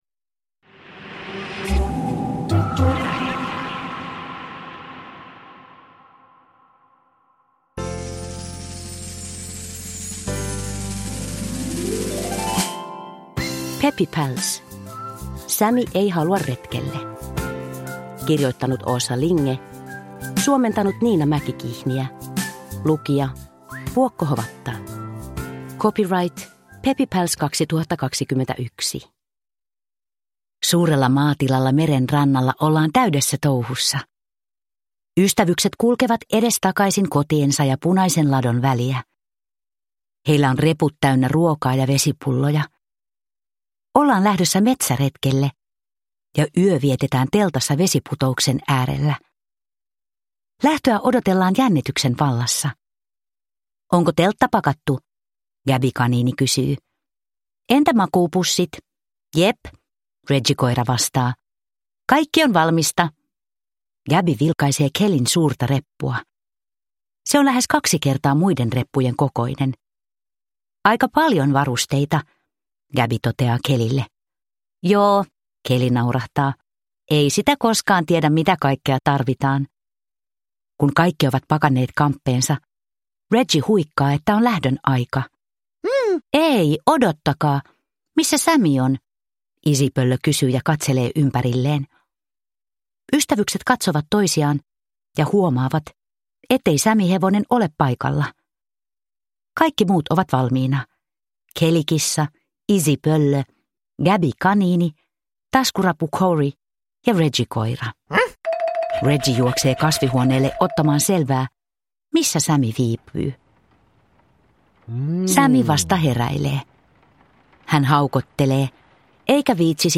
Peppy Pals: Sammy ei halua retkelle – Ljudbok – Laddas ner